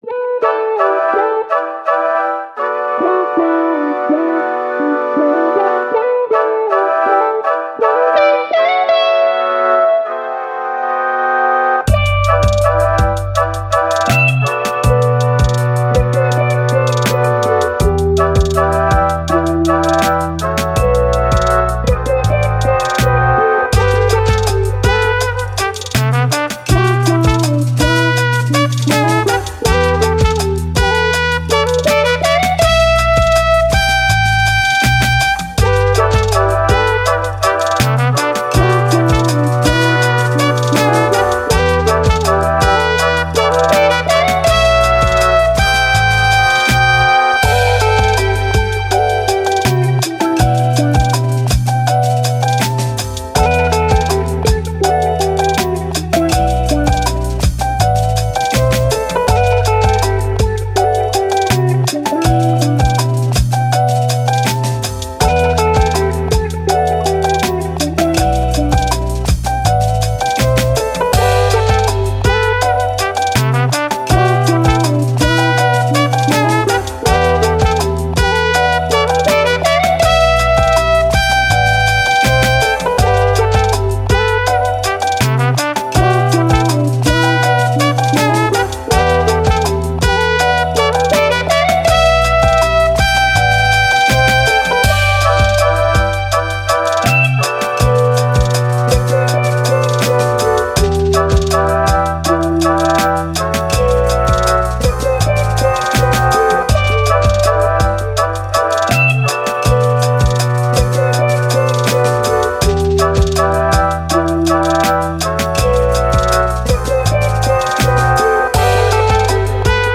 Soul, Vintage, Chilled, Brass